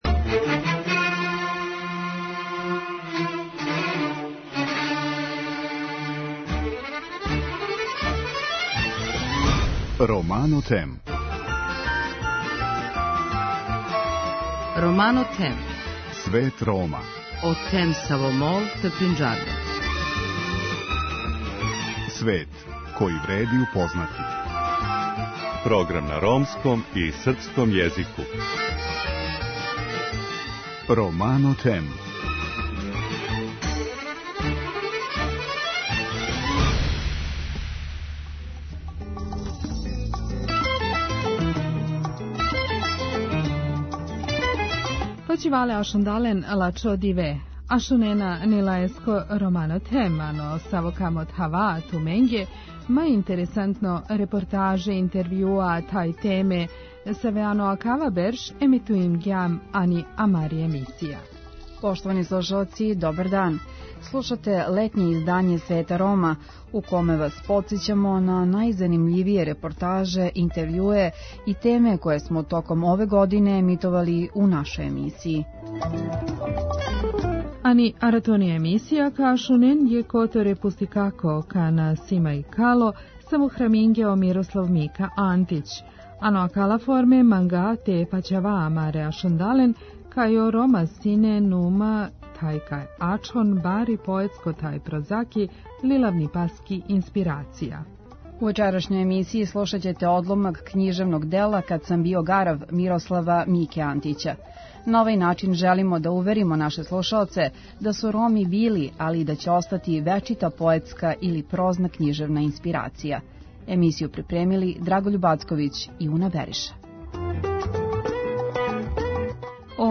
У вечерашњој емисији слушамо одломке из књиге Мирослава Мике Антића "Кад сам био гарав", чиме желимо и да илуструјемо чињеницу да су Роми били и остали велика литерарна инспирација.